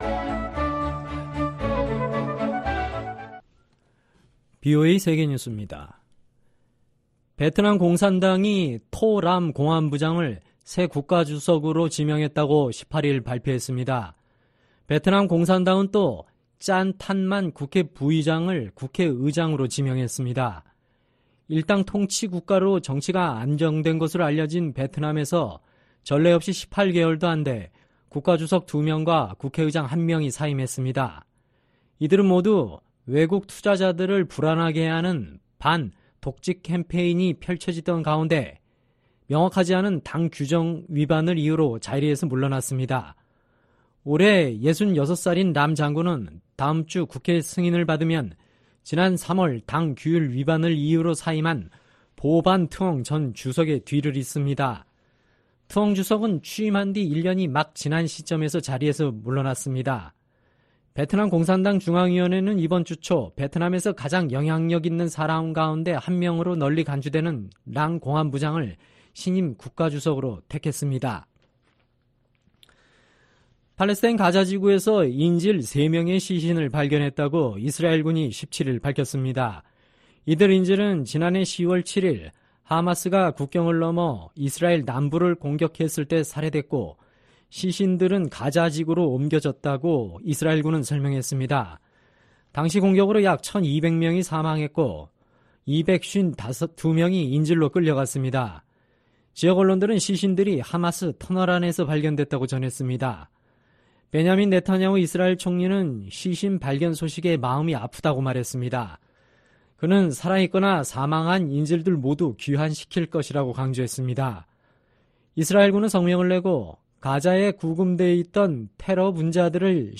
VOA 한국어 방송의 토요일 오후 프로그램 4부입니다.